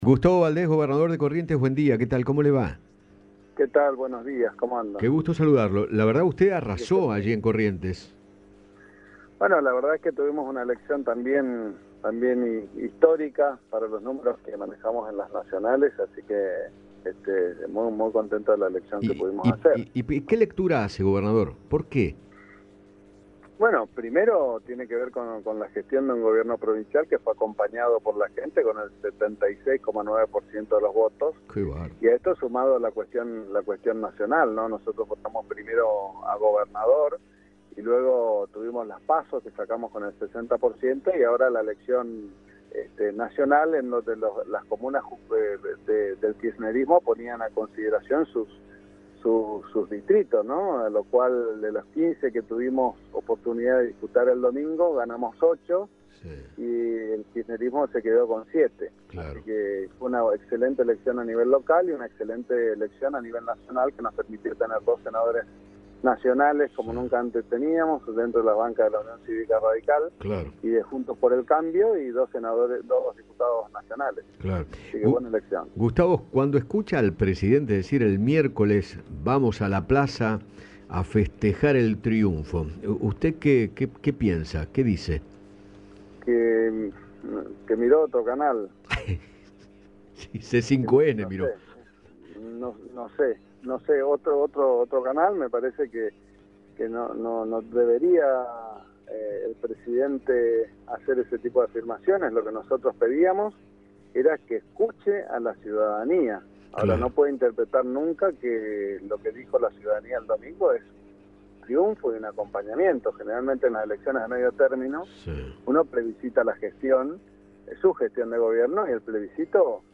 Gustavo Valdés, gobernador de Corrientes, conversó con Eduardo Feinmann sobre el enorme triunfo de Juntos por el Cambio en todo el país.